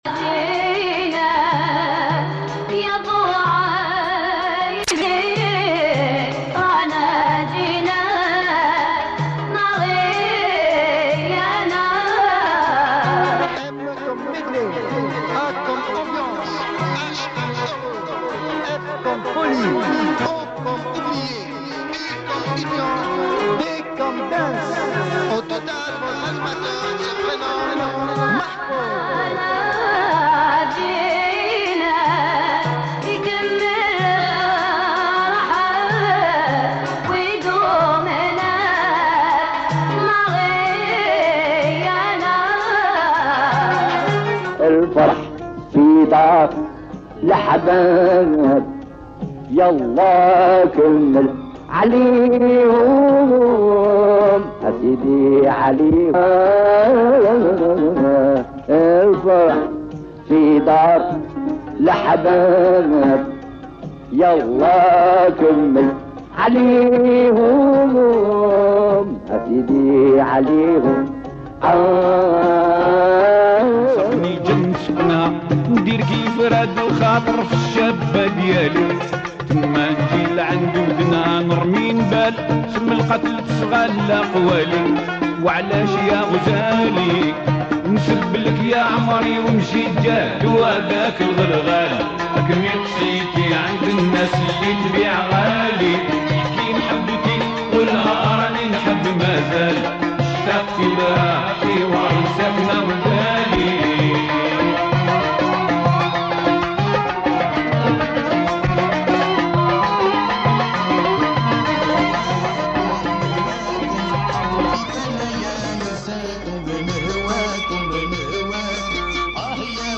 07-Jul-12 RADIO CHAABI